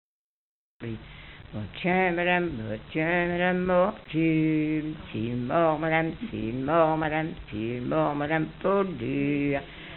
Mémoires et Patrimoines vivants - RaddO est une base de données d'archives iconographiques et sonores.
mazurka, appelée valse vienne
danse : mazurka
Pièce musicale inédite